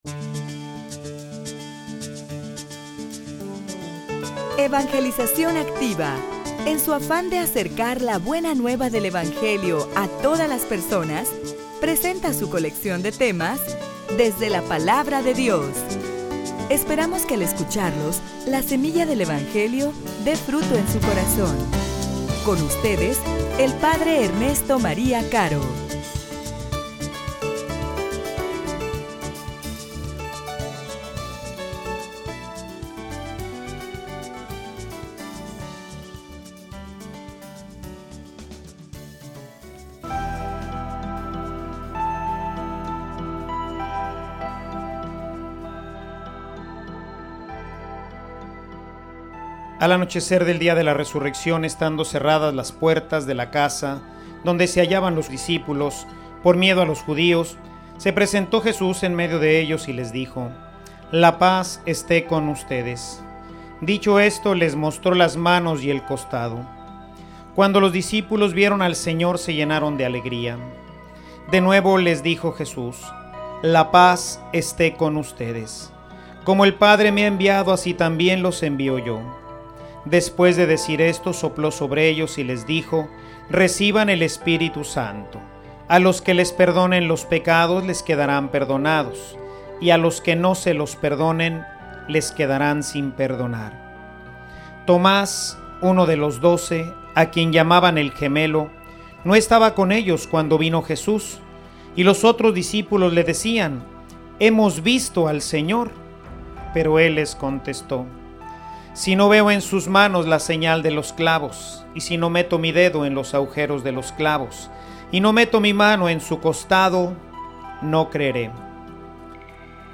homilia_Las_senales_del_resucitado.mp3